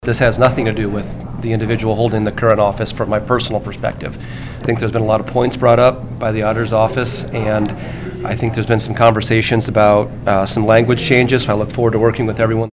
Representative Amy Nielsen, a Democrat from North Liberty, says Republicans are trying to limit the authority of State Auditor Rob Sand, the only Democrat holding statewide office.
Representative Bobby Kaufmann, a Republican from Wilton, replied.